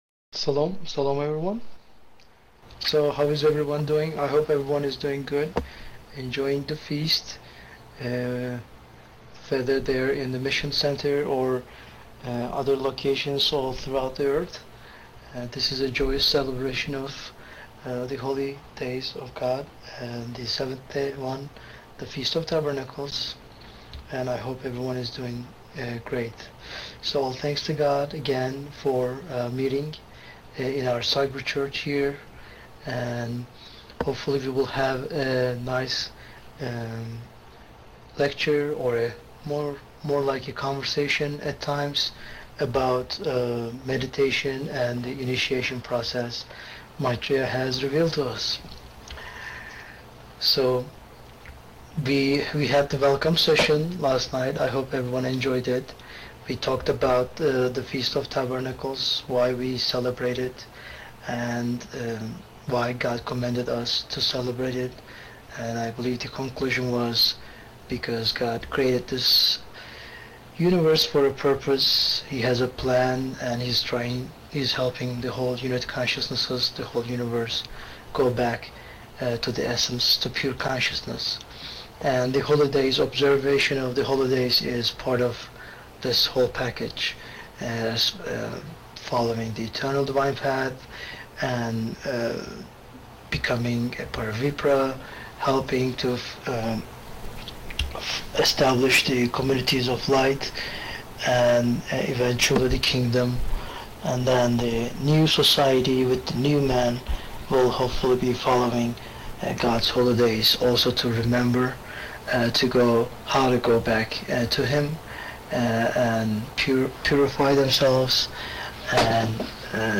Presentations Given By Disciples
Audio-MeditationInitiation-UnusFeast2015.wma